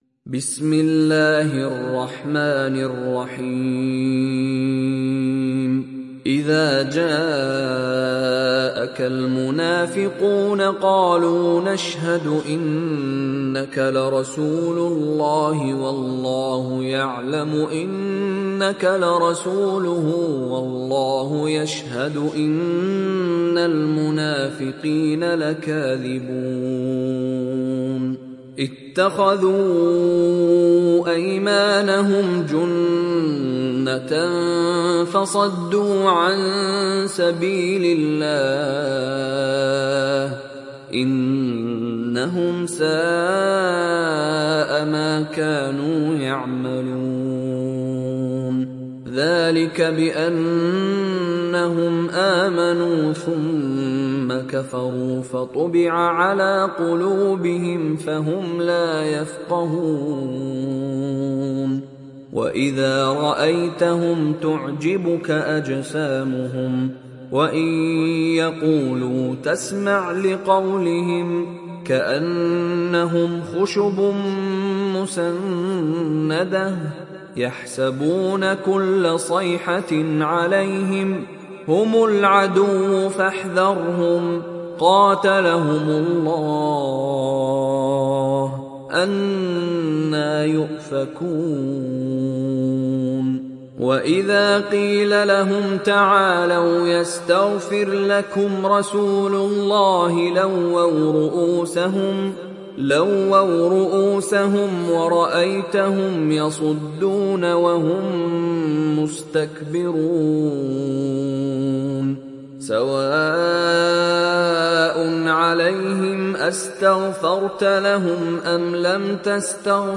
Surah Al Munafiqun Download mp3 Mishary Rashid Alafasy Riwayat Hafs from Asim, Download Quran and listen mp3 full direct links